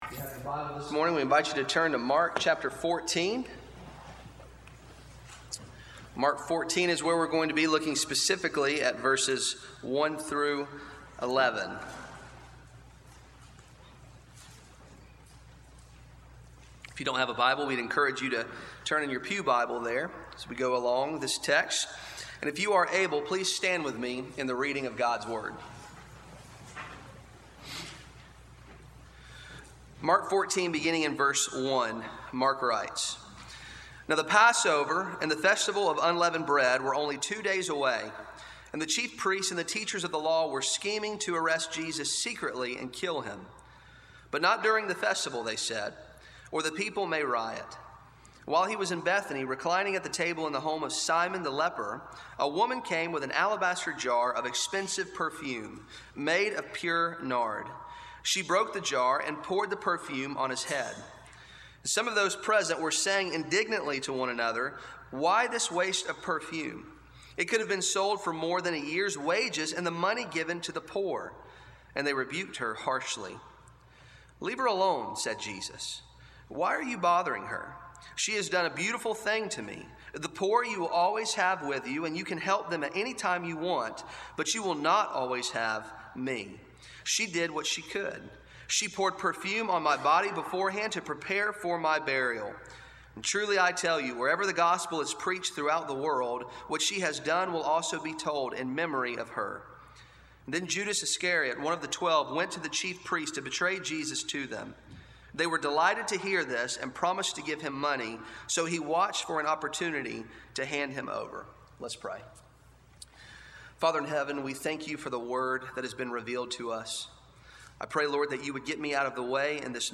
Arlington Baptist Church Sermons